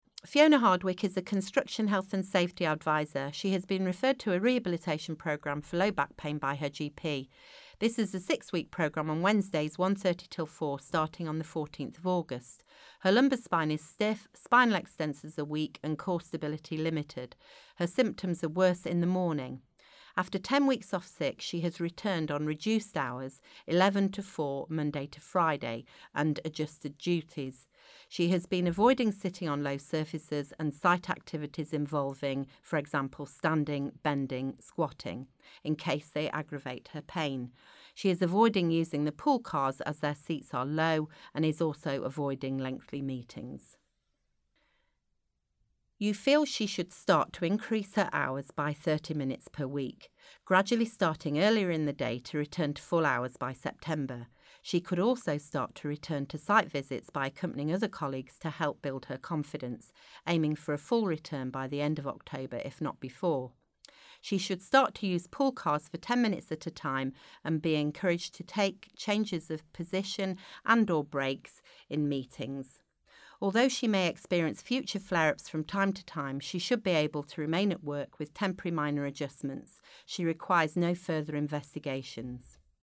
Case history narration audio (MP3)
rlo-create-section4.1-case-narration.mp3